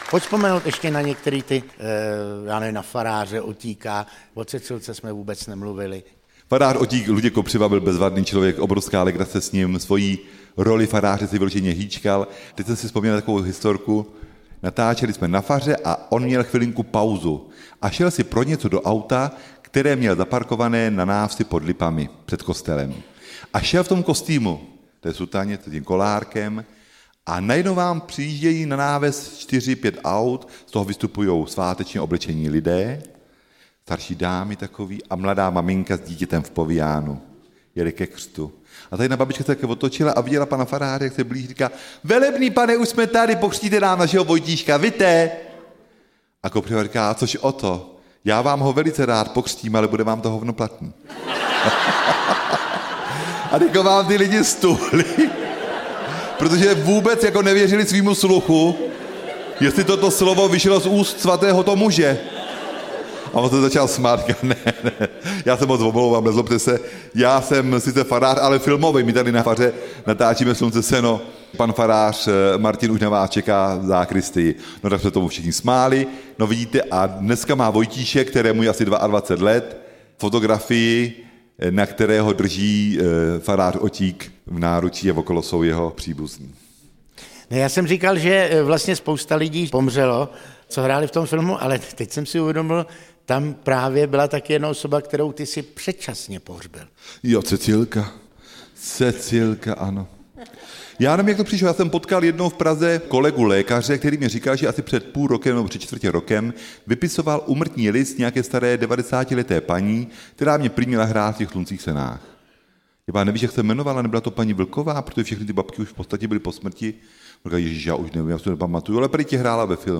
3x Zdeněk Troška audiokniha
Ukázka z knihy
Filmový režisér Zdeněk Troška nás mnohokrát přesvědčil, že je vtipným vypravěčem historek z natáčení i ze života. Již několik let vystupuje před diváky po celé republice se zájezdovým programem plným veselých vyprávění, zábavy a smíchu.